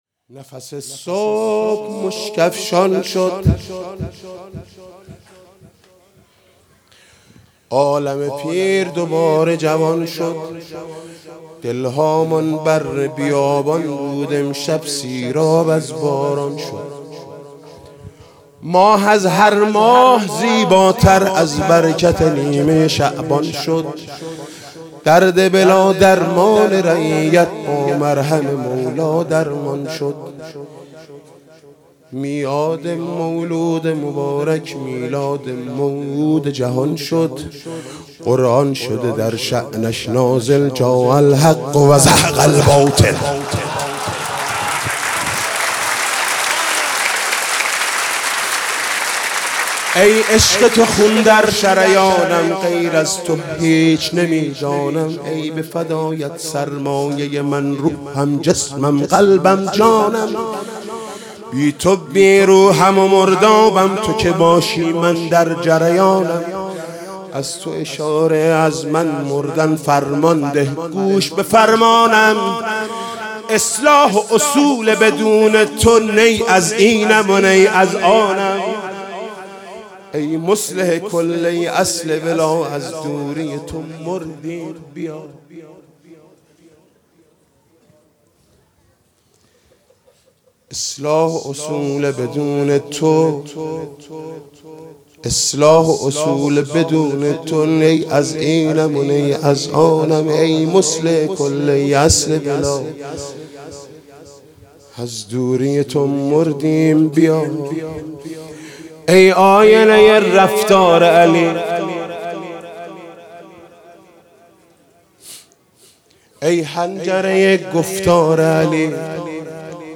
مدح: نفس صبح مشک افشان شد